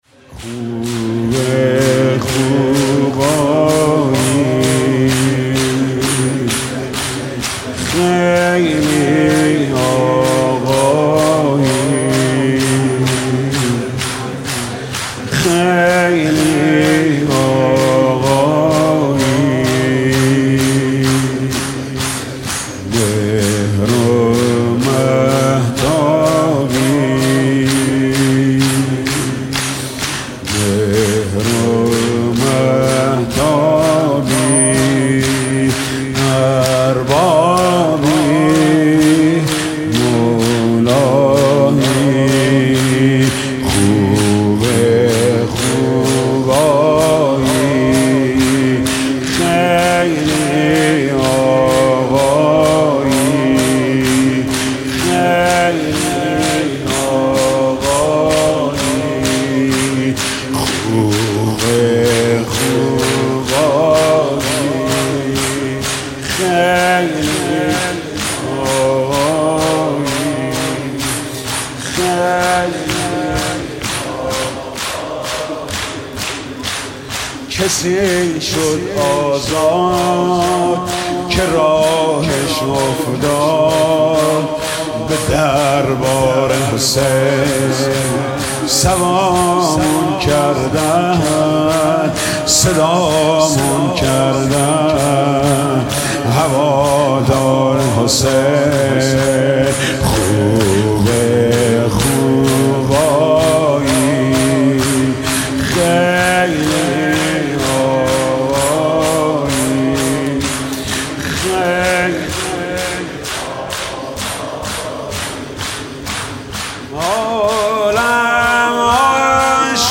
صوت | مداحی زیبا به مناسبت شب زیارتی امام حسین